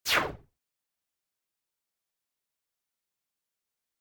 missile.ogg